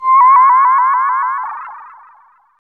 SI2 BEES  06.wav